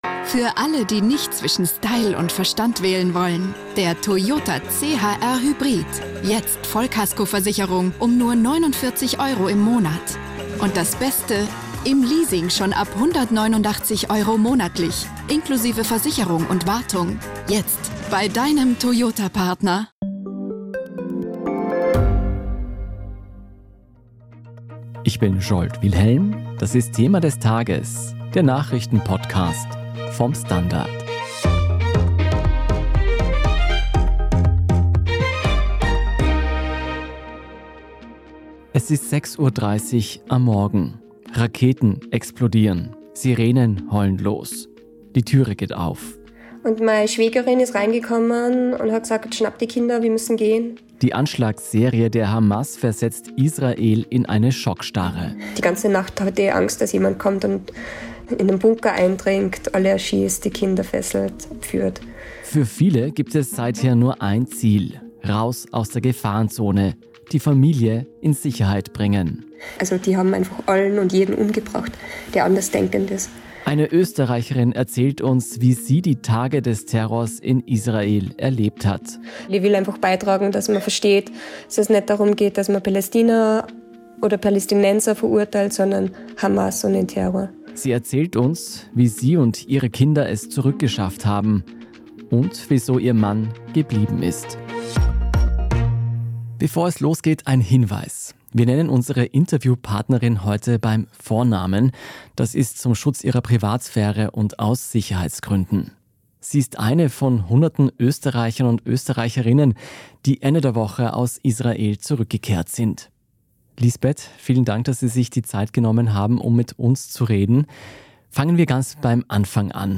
Dieses Interview wurde in Thema des Tages geführt.